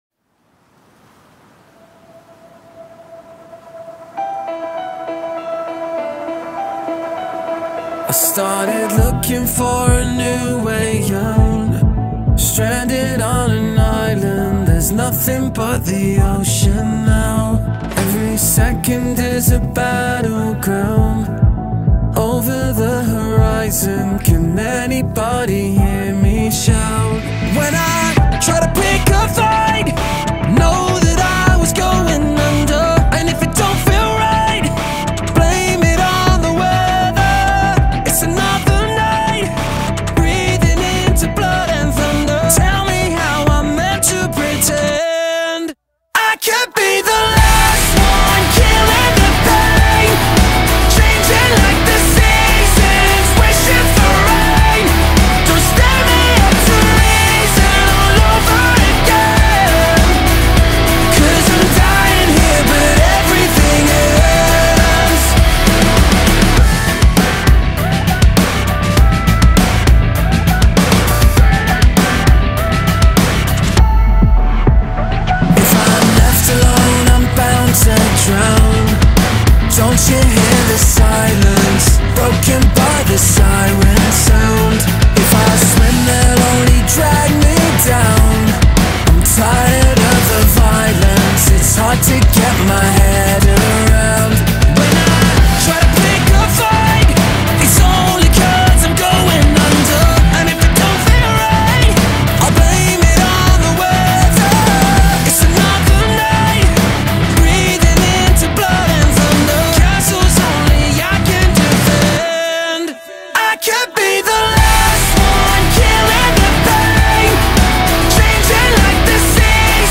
This is heaviness with intent.